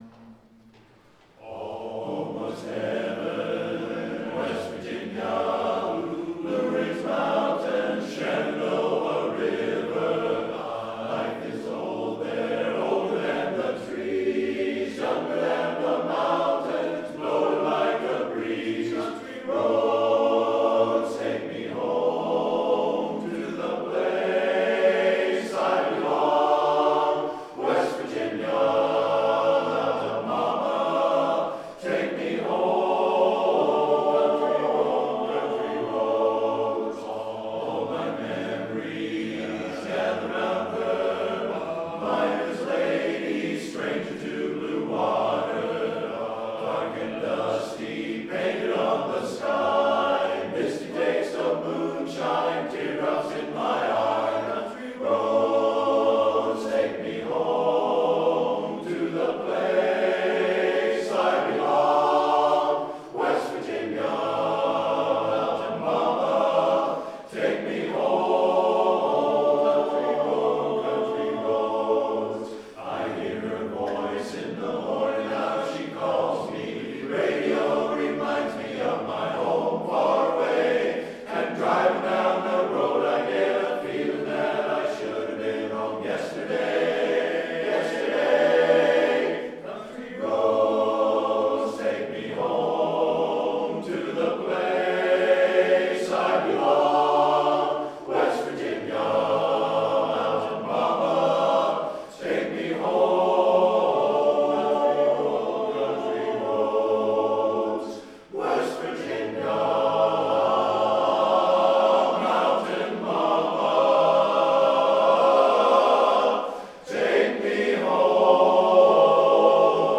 Up-tempo
B♭ Major
Full Mix